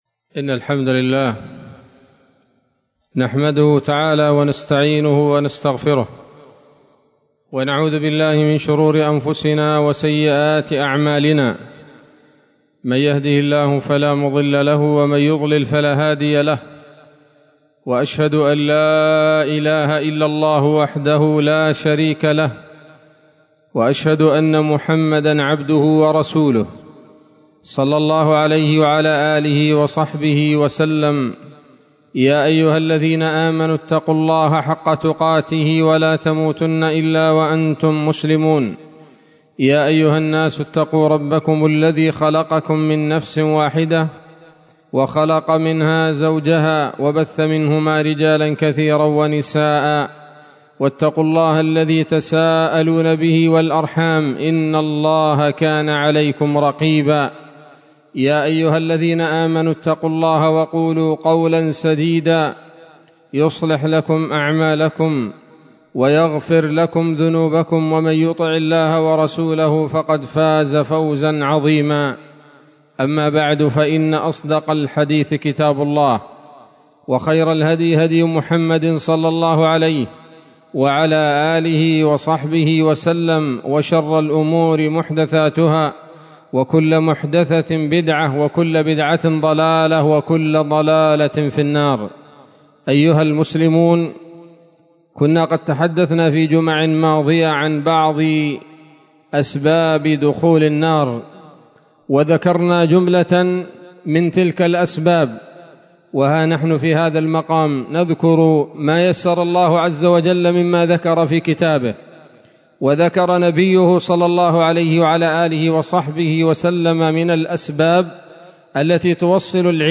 خطبة جمعة بعنوان: (( أسباب دخول النار [2] )) 18 ربيع أول 1444 هـ، دار الحديث السلفية بصلاح الدين
أسباب-دخول-النار-2ـ-خطبة.mp3